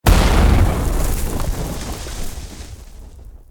grasping_hands_start.ogg